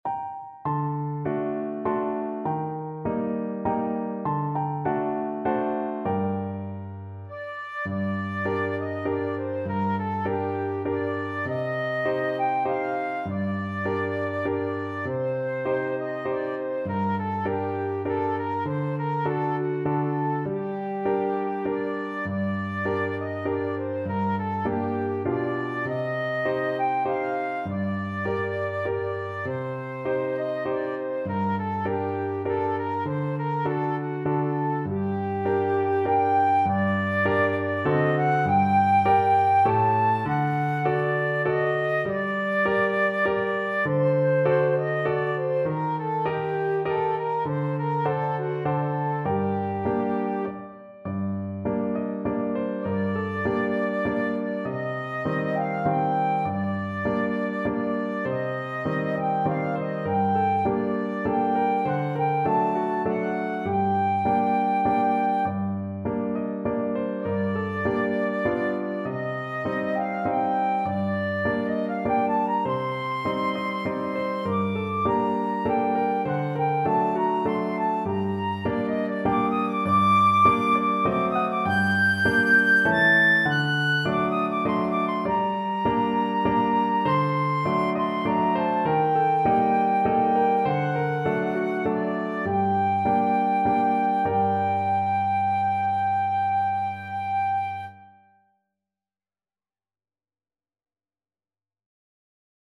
Flute
G minor (Sounding Pitch) (View more G minor Music for Flute )
Moderato =c.100
Traditional (View more Traditional Flute Music)